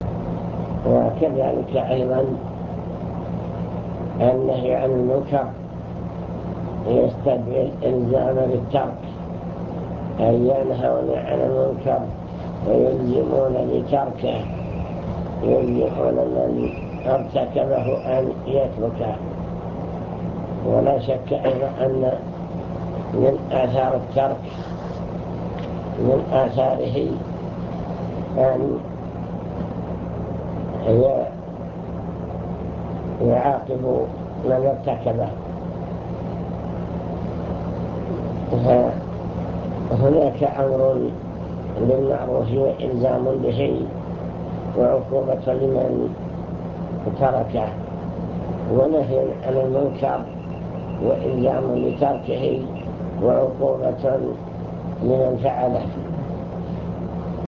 المكتبة الصوتية  تسجيلات - لقاءات  كلمة في الهيئة